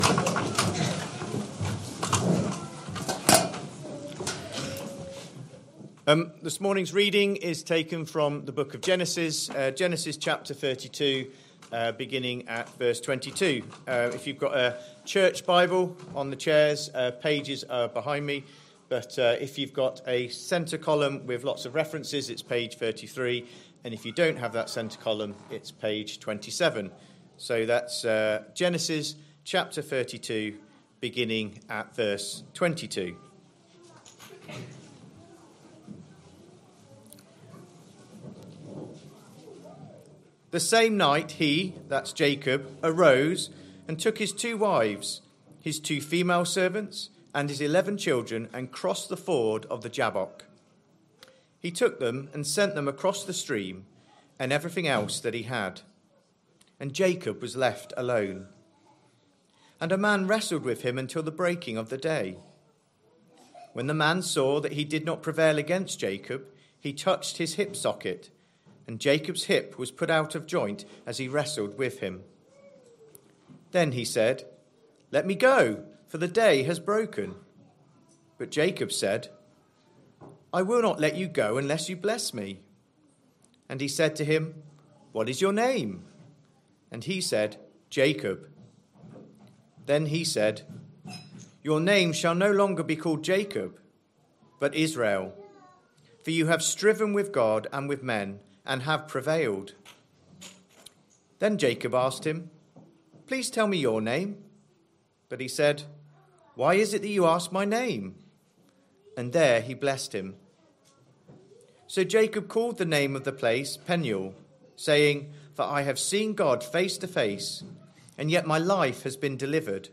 Christ Church Sermon Archive
Sunday AM Service Saturday 1st March 2025 Speaker